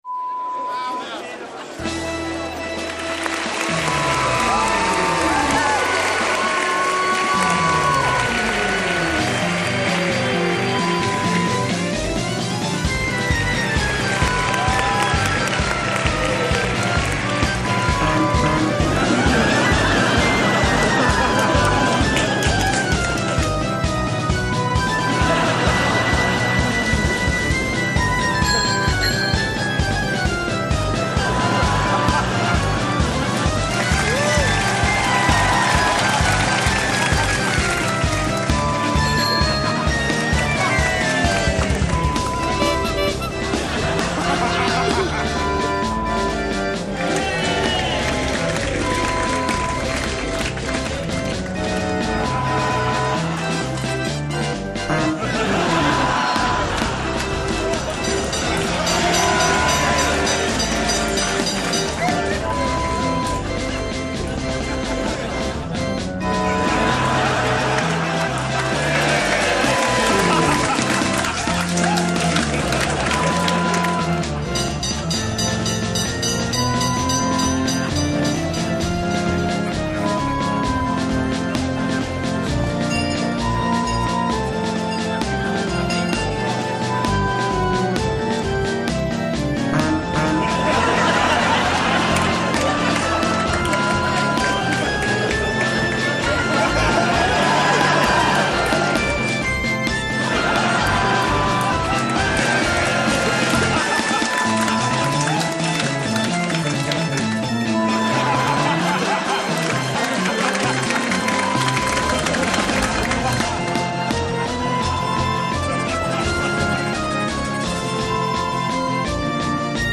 Circus Sound Effect.wav
(2.22) CIRCUS SOUNDS: A fantastic recording made at the center of a major Big Top circus. Includes circus music, crowd sounds and funny noises from the...